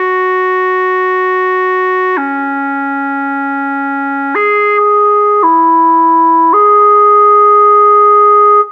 Synth Whistle 01.wav